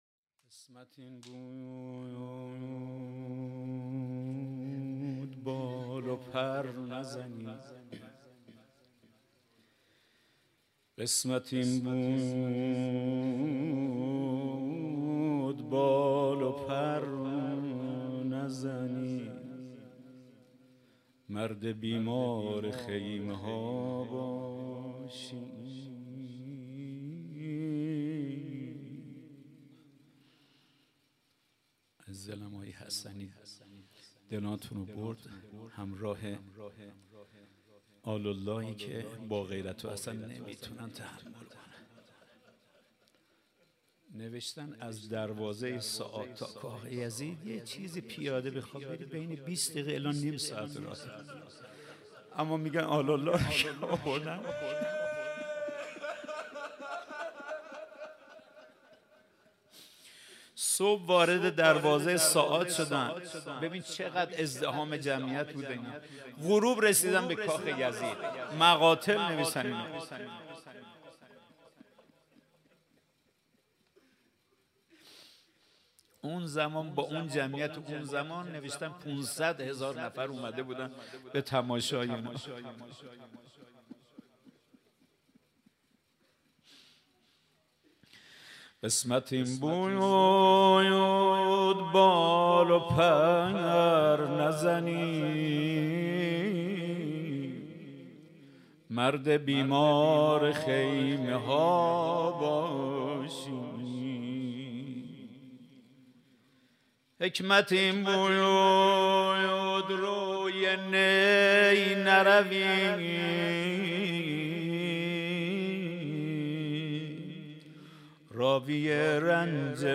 حسینیه کربلا
روضه امام سجاد